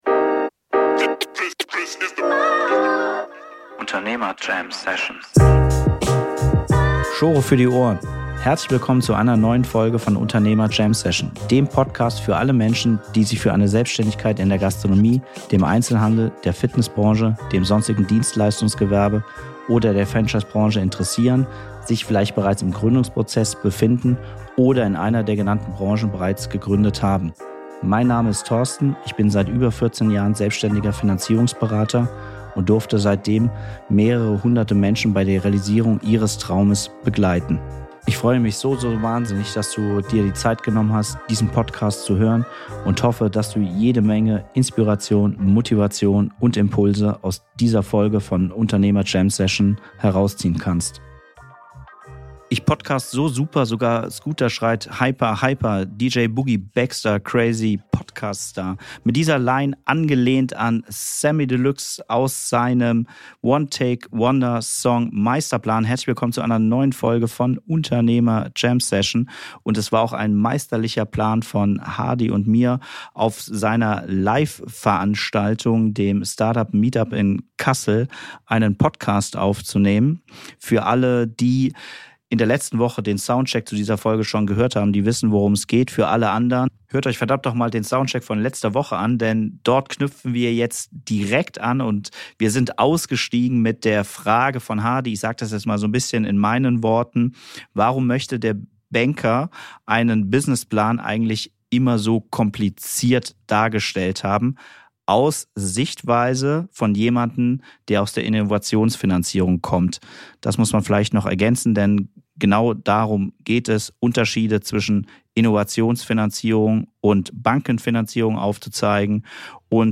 Innovations- vs. Bankenfinanzierung - Live Q&A vom MeetUp in Kassel